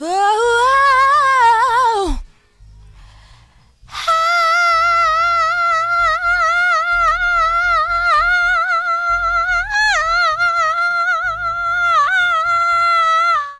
WHOO...AHHH.wav